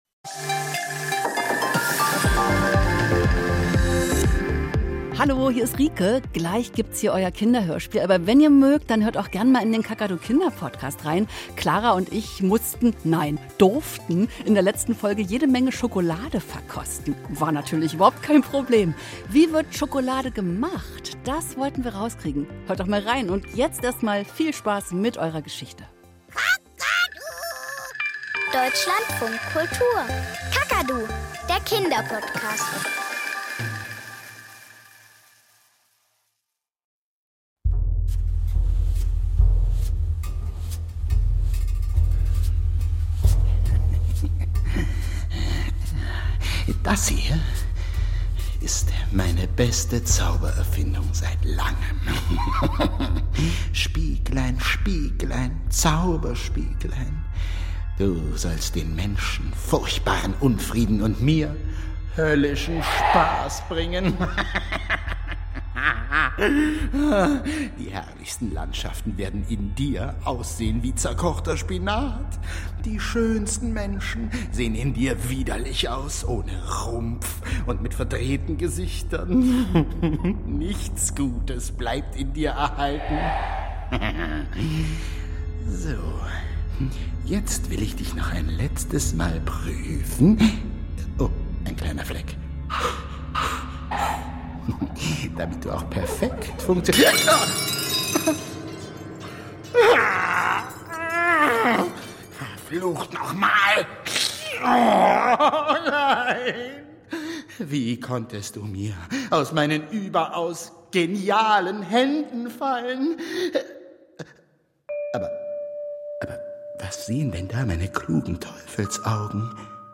Kinderhörspiel - Die Schneekönigin ~ Kakadu – Das Kinderhörspiel Podcast